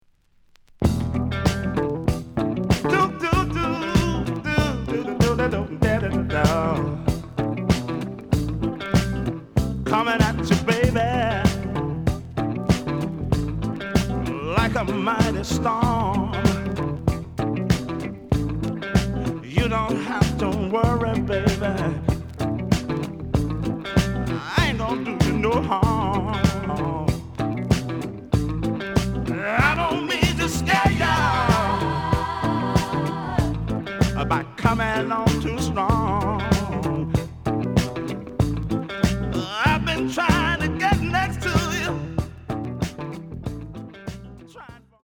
The audio sample is recorded from the actual item.
●Genre: Soul, 70's Soul
Edge warp. But doesn't affect playing. Plays good.)